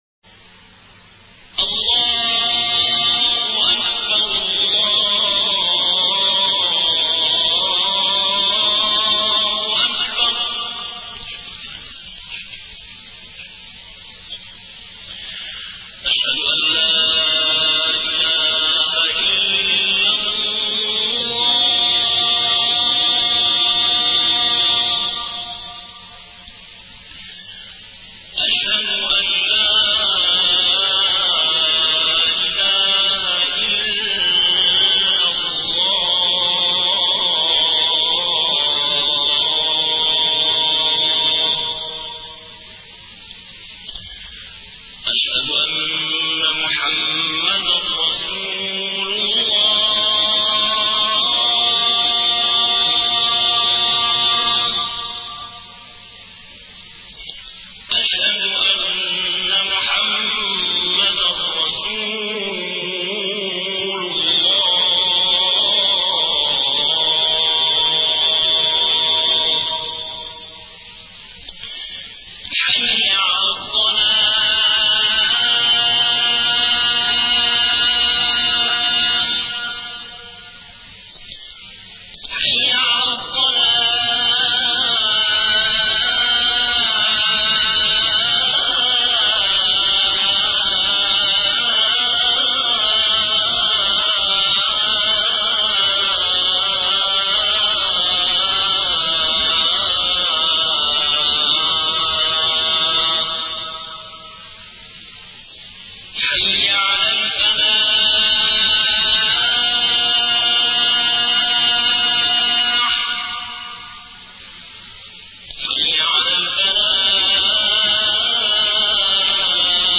عنوان المادة أذان الفجــر - جـــزائـر